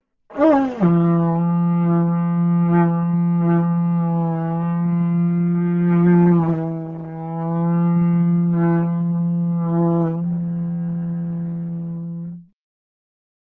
la corne (d’antilope)
Sans trous, ni pistons, ni coulisse, l’instrument ne peut évoluer mélodiquement que par la succession des premières harmoniques que le joueur obtient en modulant l’intensité de son souffle.
trompe.mp3